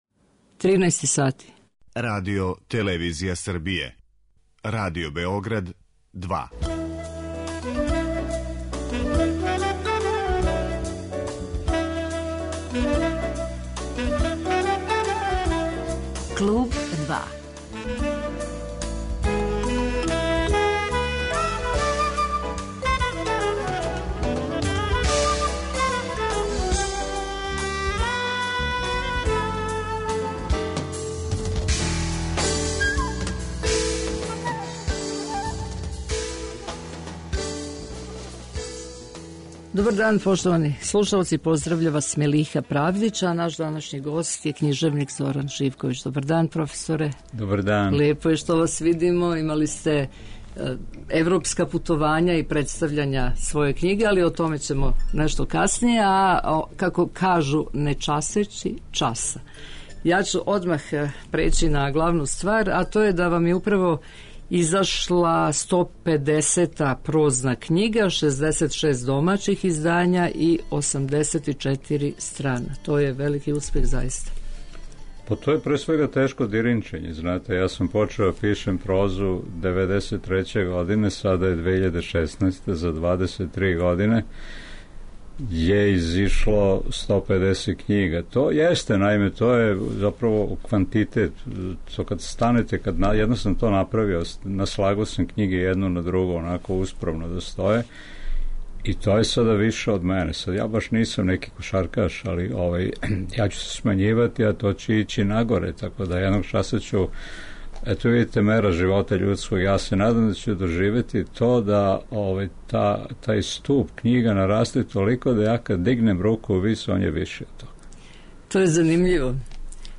Гост емисије је писац Зоран Живковић.